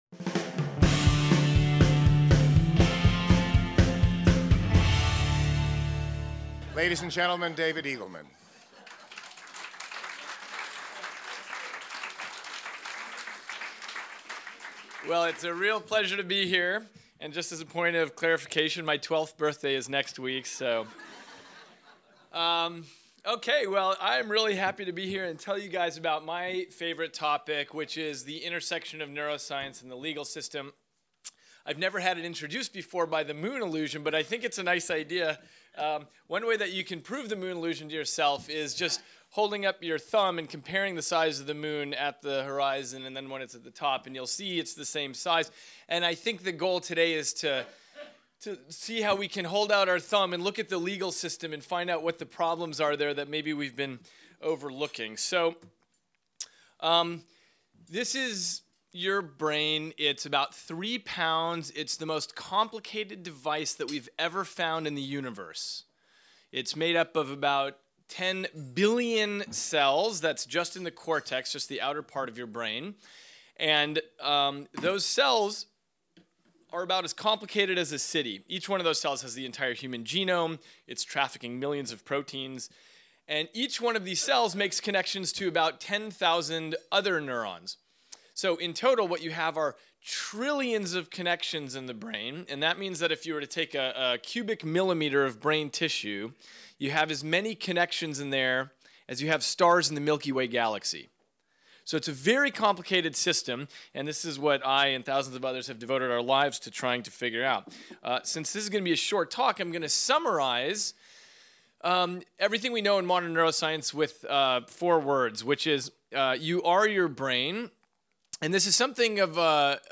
"You are your brain," insists Baylor University neuroscientist and best-selling writer David Eagleman.